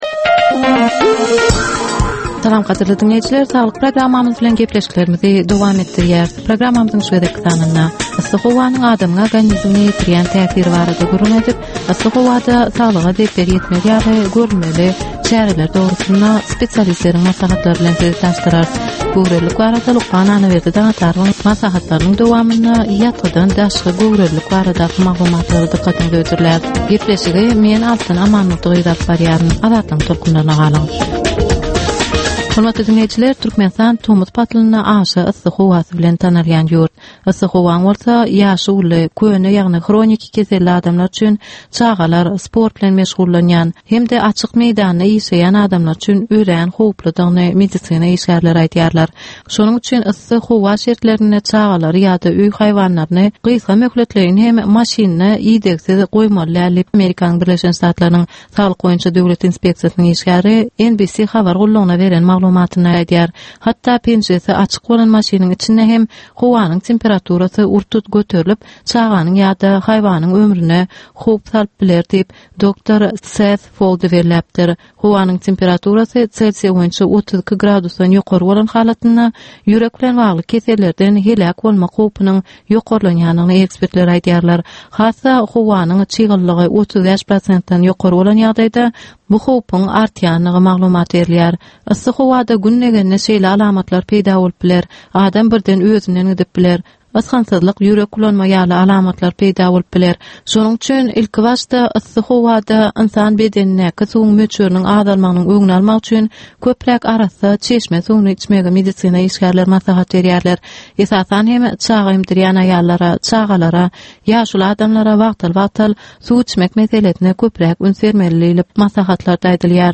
Ynsan saglygyny gorap saklamak bilen baglanysykly maglumatlar, täzelikler, wakalar, meseleler, problemalar we çözgütler barada 10 minutlyk ýörite geplesik.